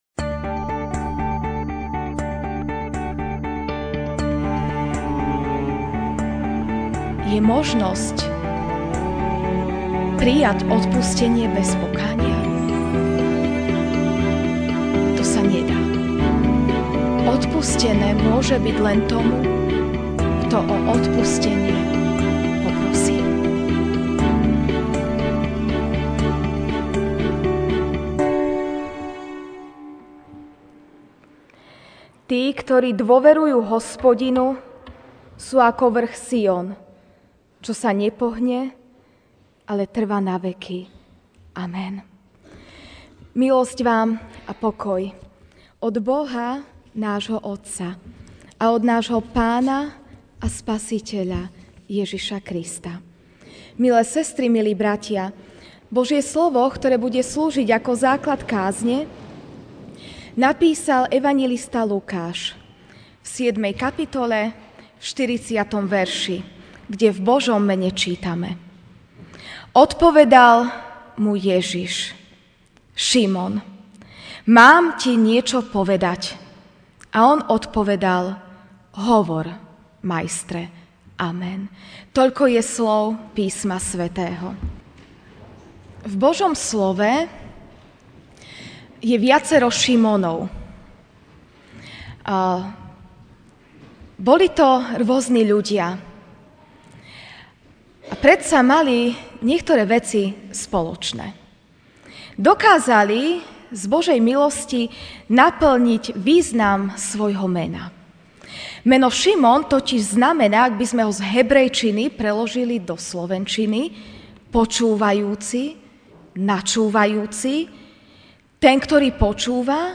Ranná kázeň: Si ochotný počúvať? (L 7, 40)Odpovedal mu Ježiš: Šimon, mám ti niečopovedať.